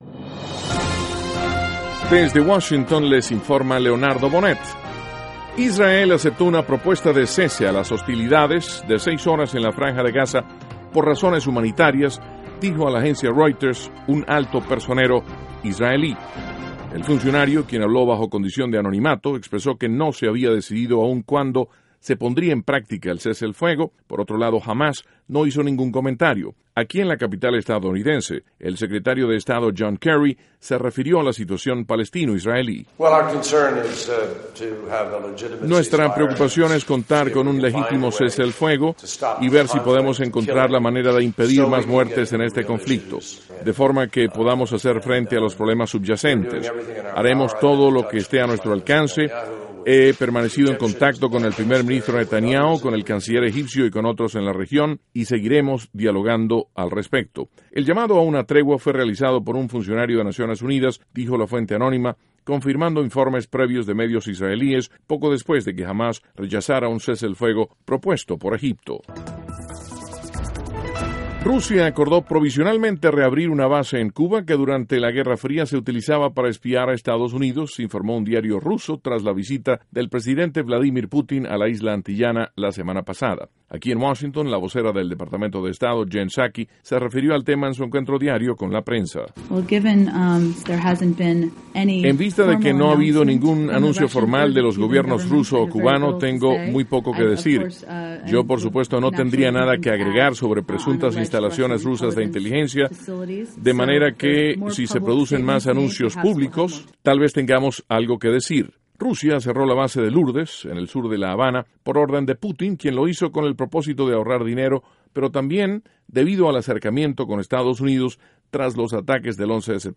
NOTICIAS - MIÉRCOLES, 16 DE JULIO, 2014
Duración: 3:30 Contenido: 1.- Israel acepta cese temporal de hostilidades, por razones humanitarias. (Sonido – John Kerry) 2.- Rusia acuerda reabrir base de inteligencia en Cuba.